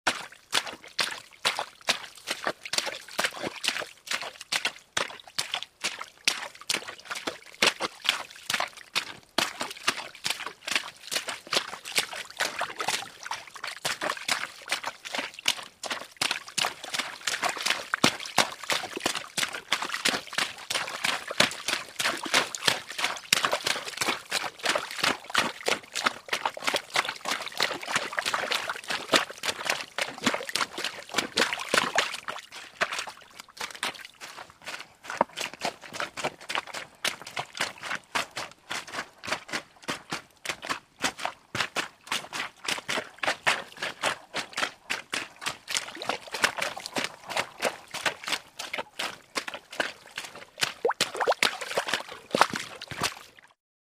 На этой странице собраны натуральные звуки шагов по лужам: от легкого шлепанья до энергичного хлюпанья.
Шаги по мокрому асфальту звучат живо